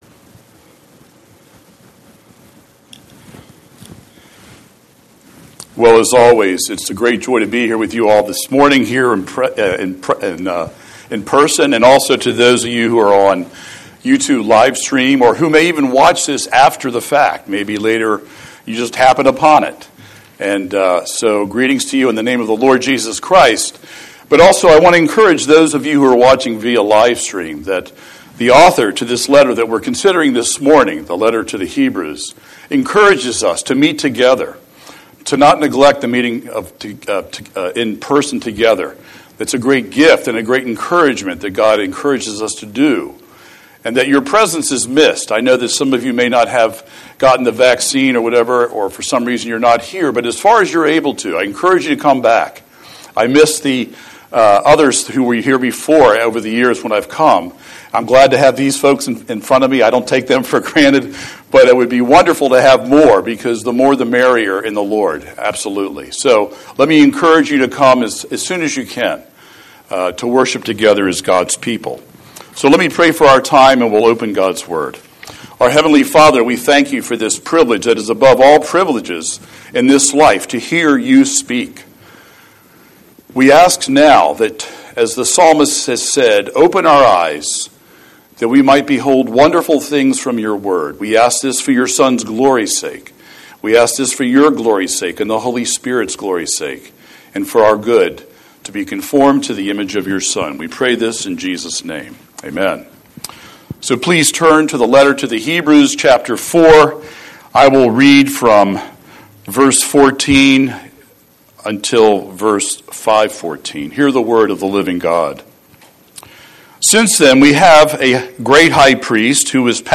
Scripture: Hebrews 4:14–5:14 Series: Sunday Sermon